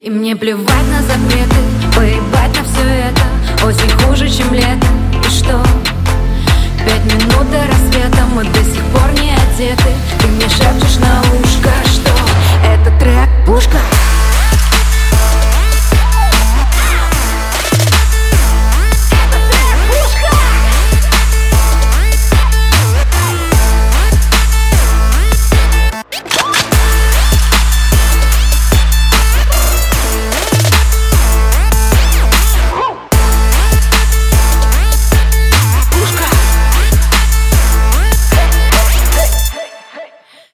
• Качество: 128, Stereo
рэп